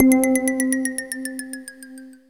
SI2 RAIN.wav